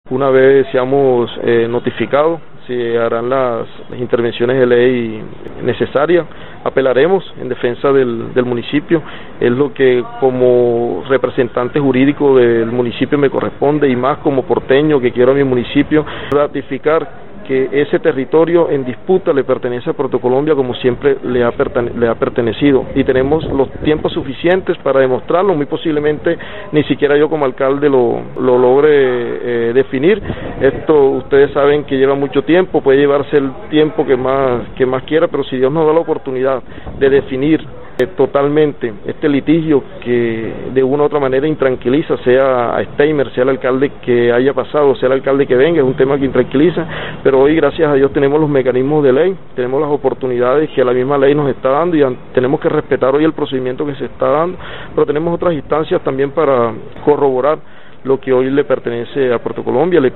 Steimer Mantilla, alcalde del municipio de Puerto Colombia, en diálogo con Atlántico en Noticias señaló que una vez sea notificado del fallo del Tribunal, lo apelará en defensa de los intereses del municipio.